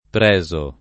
[ pr $@ o ]